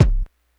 kick02.wav